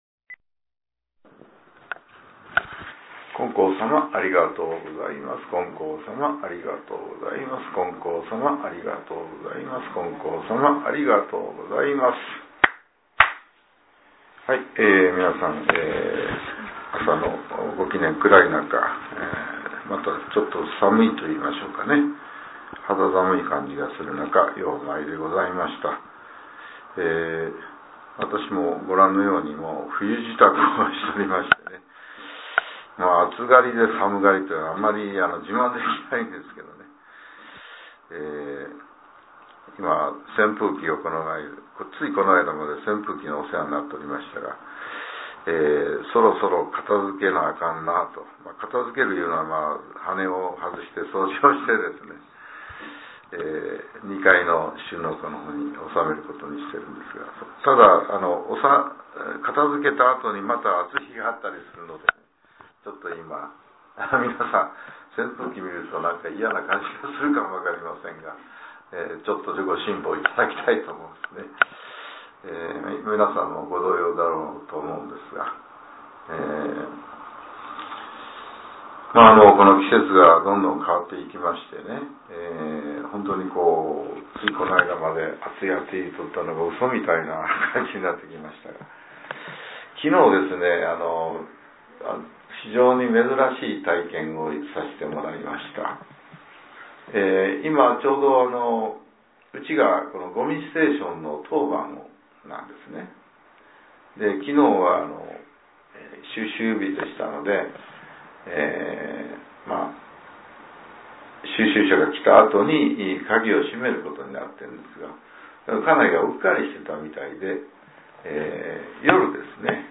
令和７年１０月２８日（朝）のお話が、音声ブログとして更新させれています。